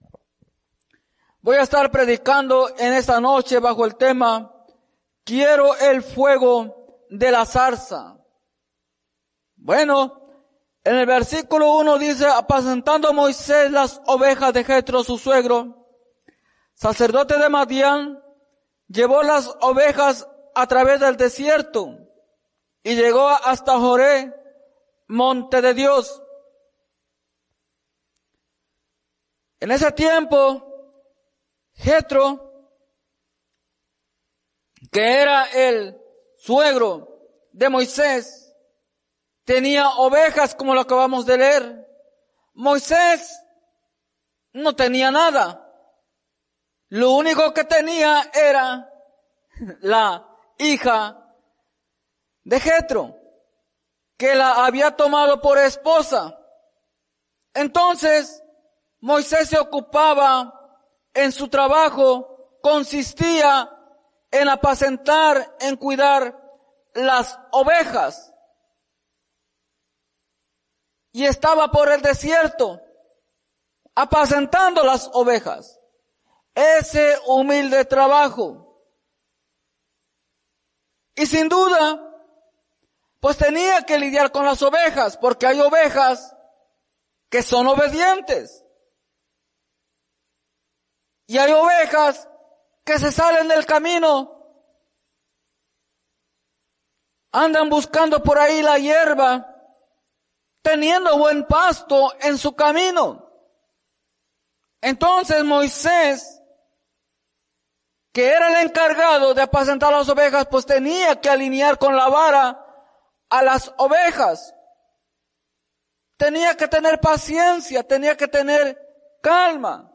Mensaje
en la Iglesia Misión Evangélica en Norristown, PA